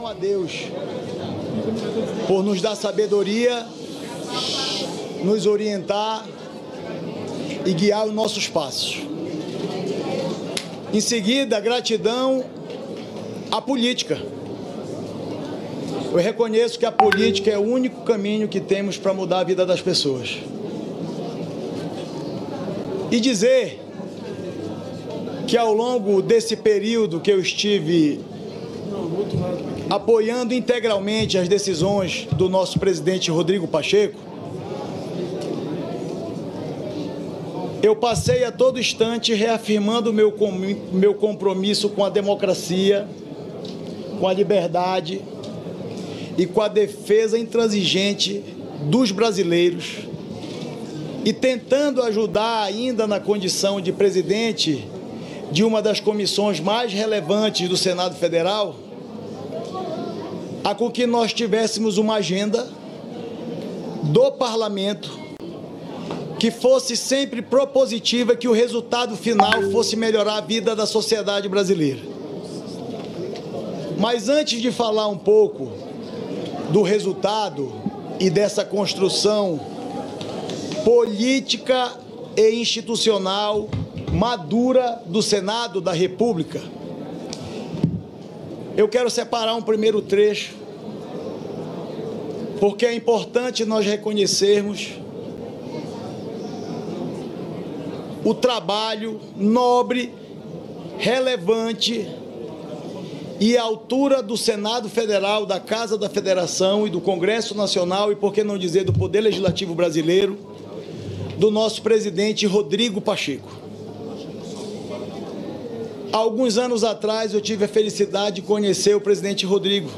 Ao final da sessão que elegeu o novo presidente do Senado, o senador Davi Alcolumbre fez uma declaração à imprensa. O novo presidente do Senado defendeu o fim da polarização política e disse que o governo federal terá do Legislativo o apoio necessário para fazer o Brasil crescer e melhorar a vida da população.